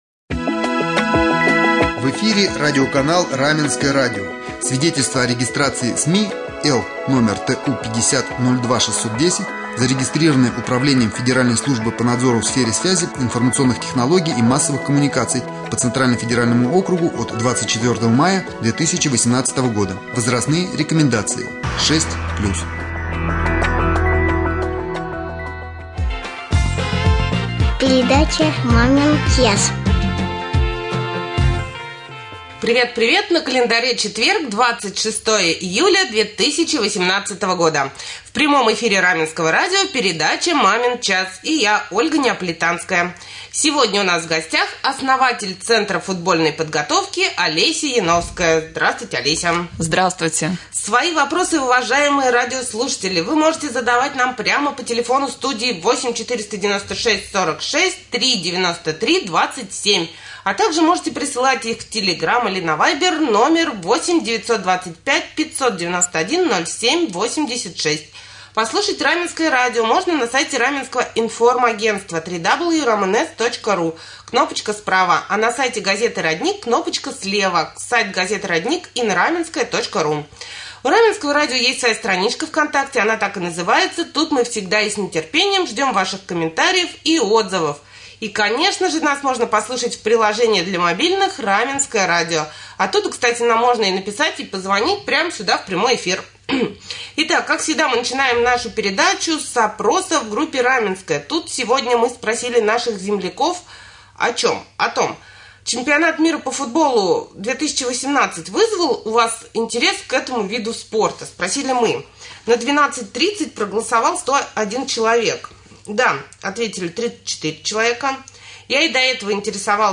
Гость эфира